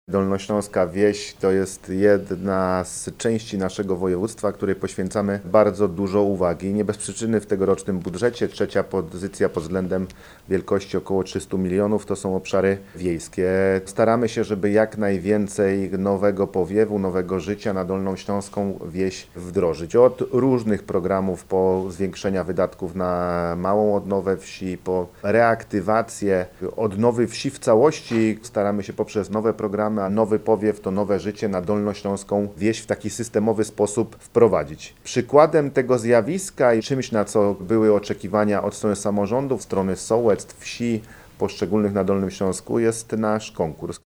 – Dolnośląska wieś to część z obszarów naszego województwa, której poświęcamy bardzo dużo uwagi. W tegorocznym budżecie blisko 300 mln zł przeznaczamy rozwój obszarów wiejskich, zaznacza Paweł Gancarz, marszałek Województwa Dolnośląskiego.